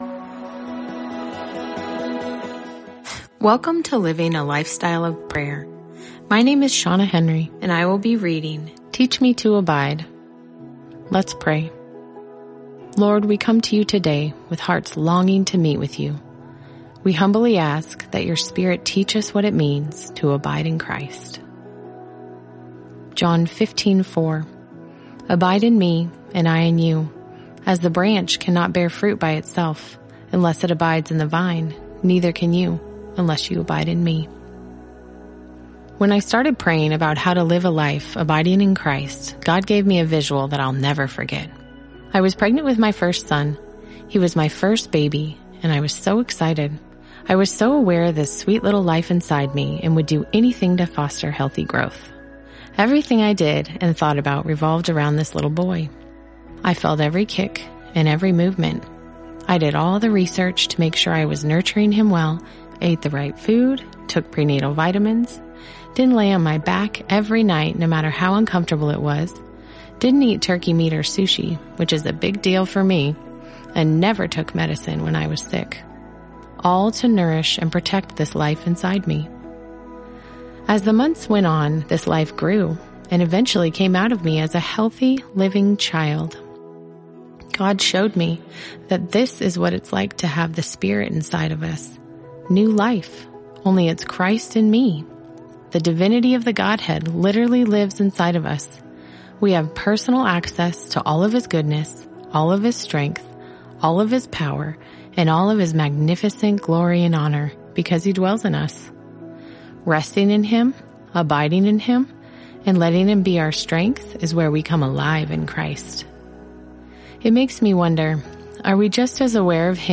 Living a Lifestyle of Prayer: 30-Day Audio Devotional for Moms